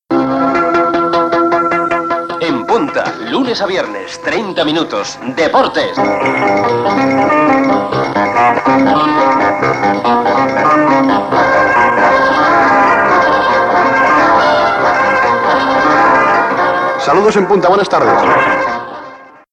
Careta del programa (veu Jordi Hurtado) i salutació inicial
Esportiu